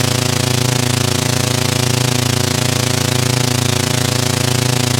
engineCircular_004.ogg